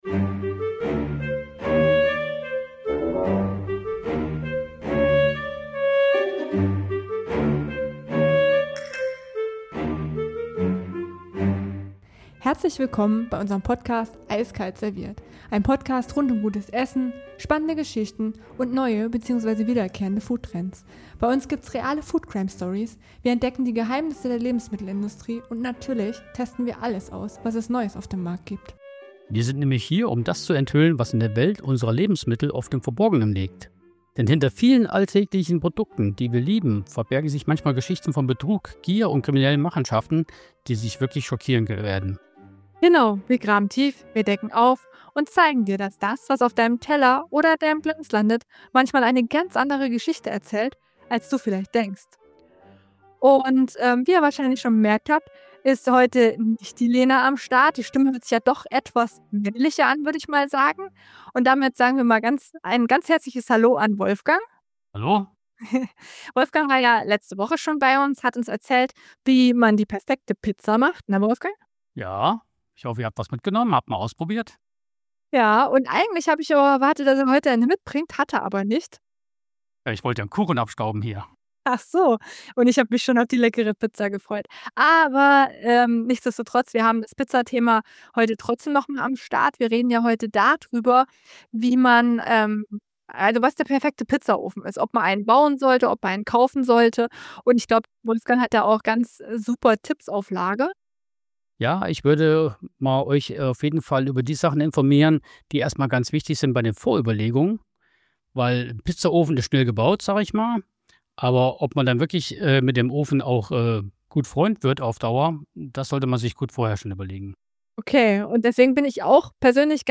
Wir beleuchten die Vor- und Nachteile beider Wege: Was kostet ein Bausatz, welche Materialien brauchst du für ein DIY-Projekt und wie viel Zeit musst du einplanen? Wir sprechen mit einem Pizzaiolo, der seinen Ofen selbst gebaut hat, und geben Tipps, worauf du bei fertigen Modellen achten solltest, von Gas- über Holz- bis zu Elektroöfen.